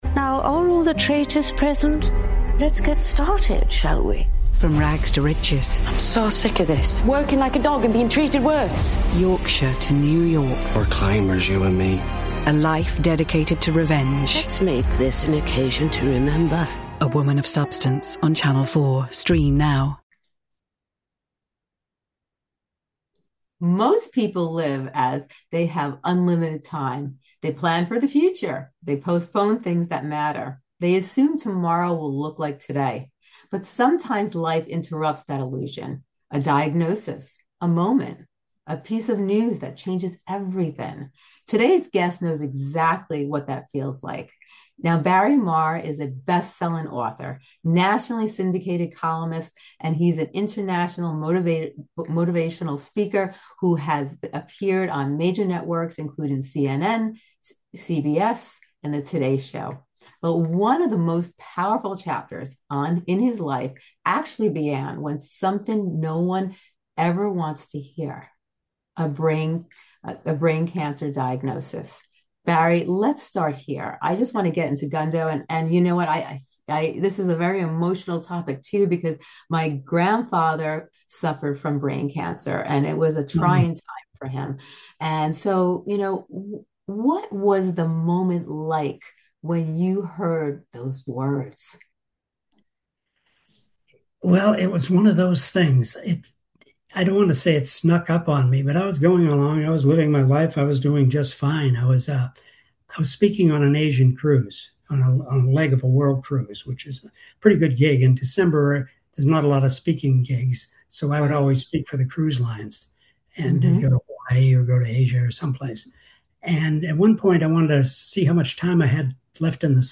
This is a powerful conversation about resilience, meaning, and learning to live more fully now.